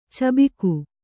♪ cabiku